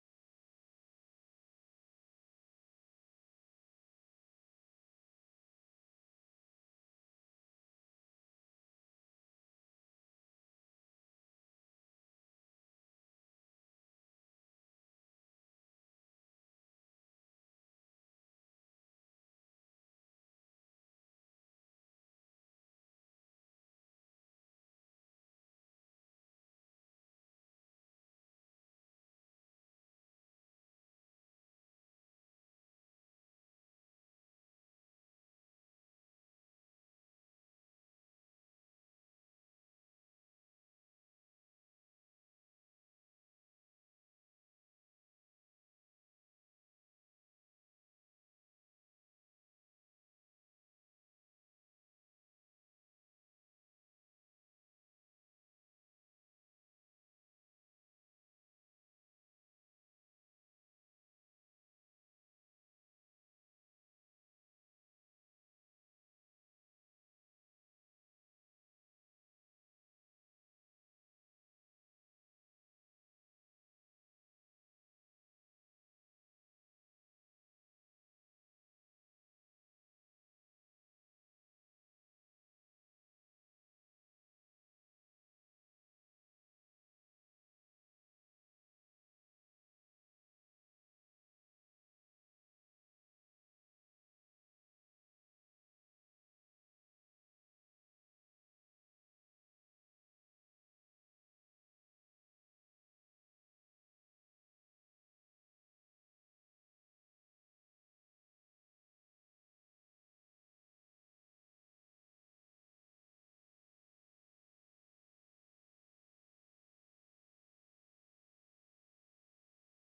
يوم ٤ محرم ١٤٤٧ هـ|| من الصحن الحسيني||